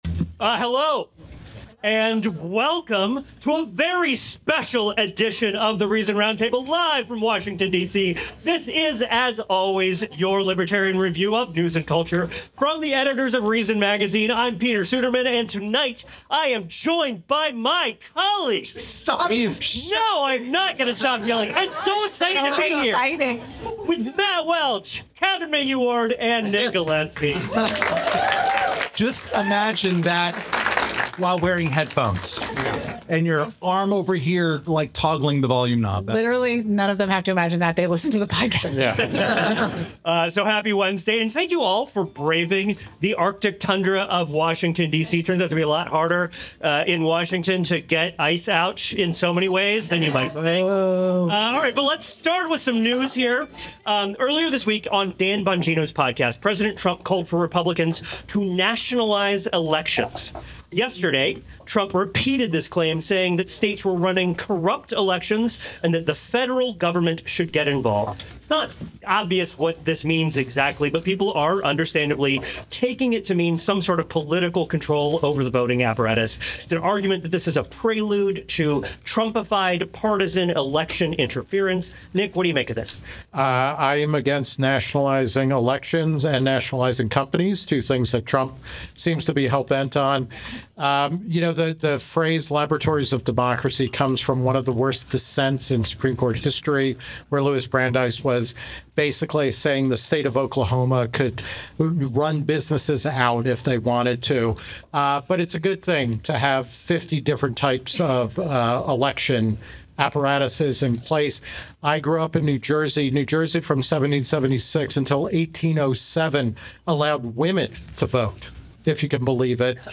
Plus: assessing Trump’s first year, the dysfunction of Washington, D.C., and the politics of the Super Bowl. (Recorded live in Washington, D.C.)